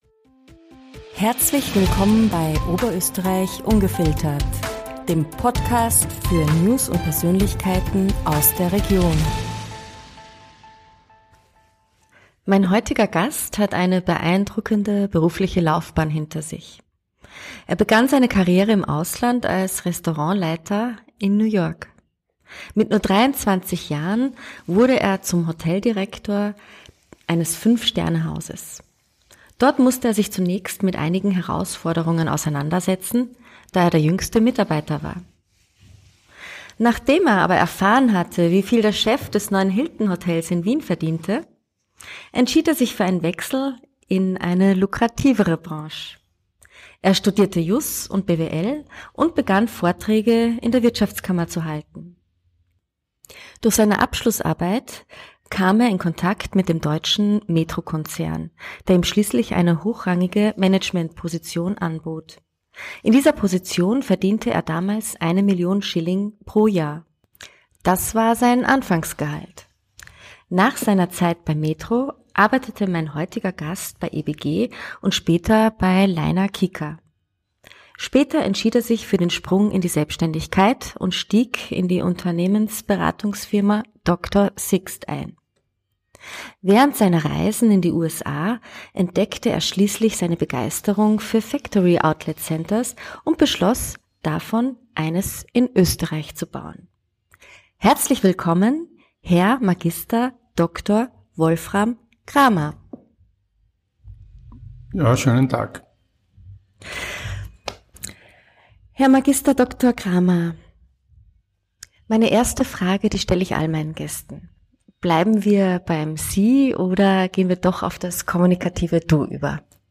Podcast-Talk ~ OÖ ungefiltert Podcast